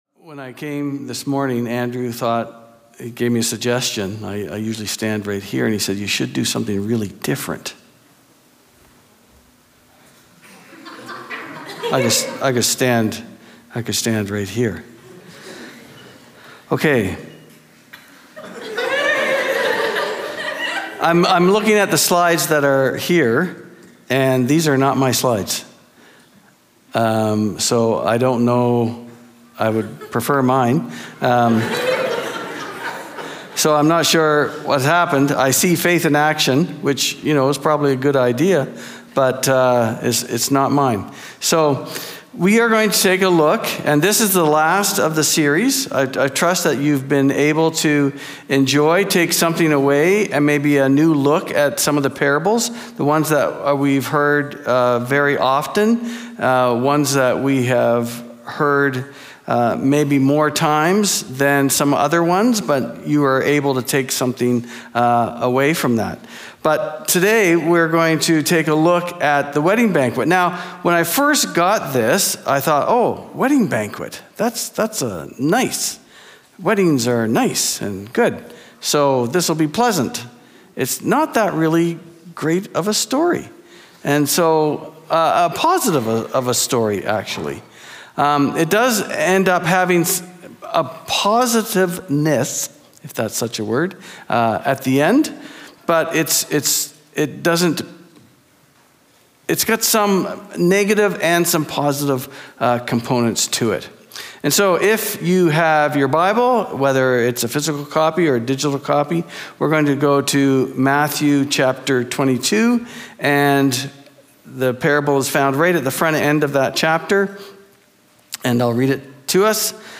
We welcome you to join us every week for a new Community Chapel service here at Prairie College.